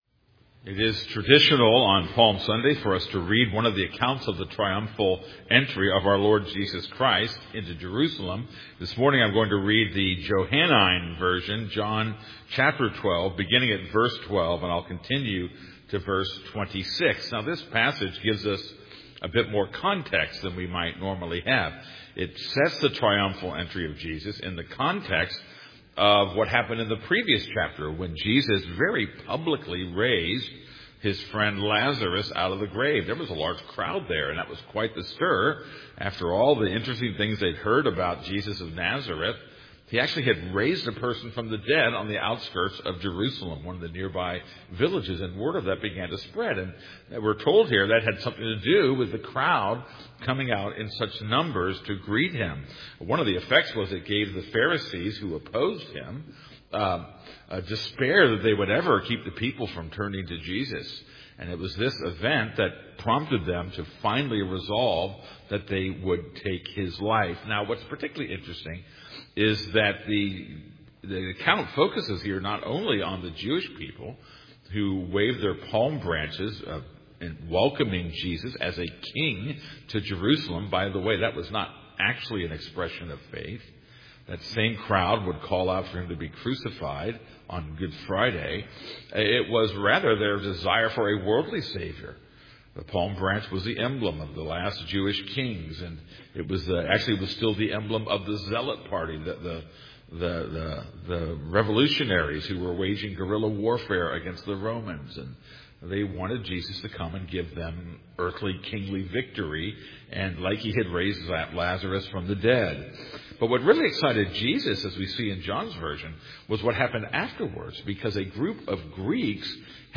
This is a sermon on John 12:12-26.